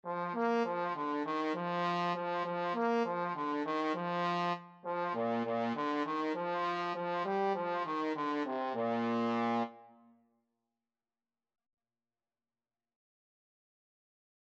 Trombone version
2/4 (View more 2/4 Music)
Bb3-Bb4
Quick
Trombone  (View more Beginners Trombone Music)